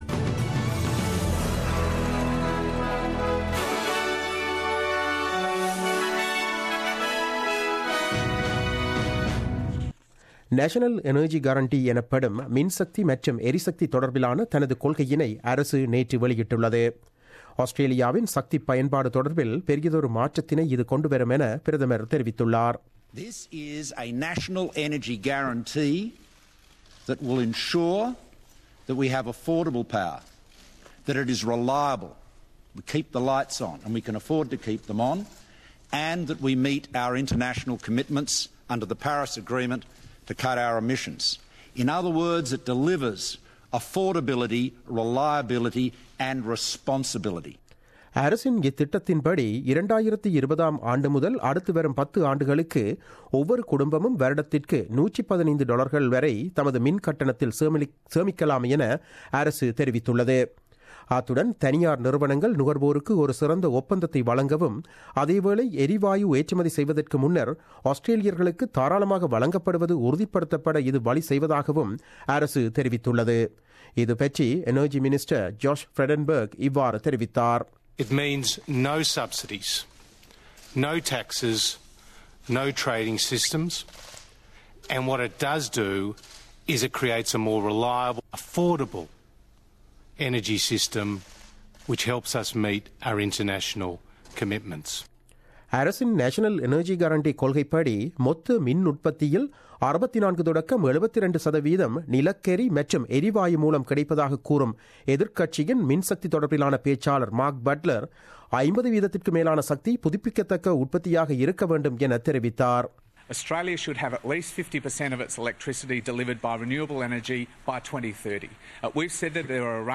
The news bulletin broadcasted on 18 October 2017 at 8pm.